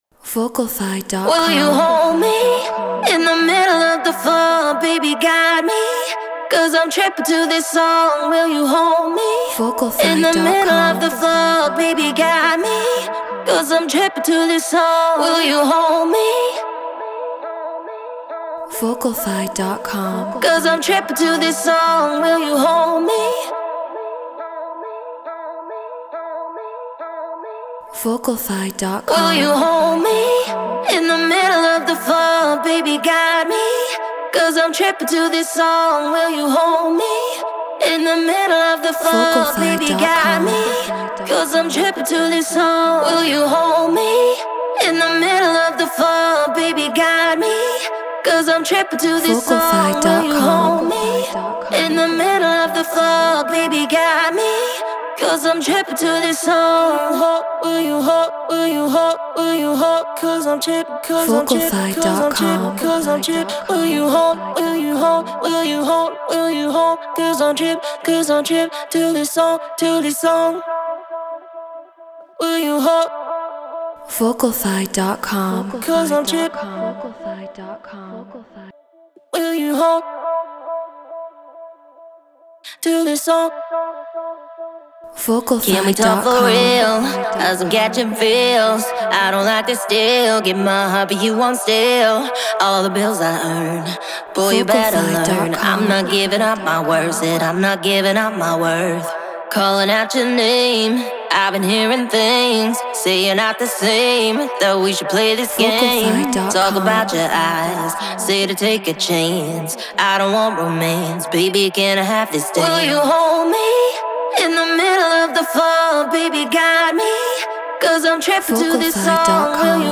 UKG 138 BPM Emin
Treated Room